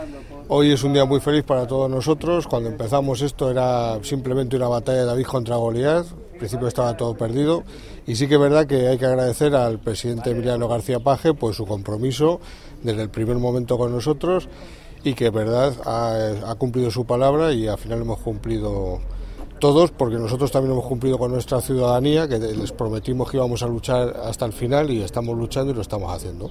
El teniente de alcalde de Alcolea del Pinar habla del apoyo del presidente García-Page a la plataforma contra el fracking de Guadalajara desde el inicio de su actividad.
teniente_alcalde_alcolea_del_pinar_fracking.mp3